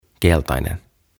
Tuhat sanaa suomeksi - Ääntämisohjeet - Sivu 4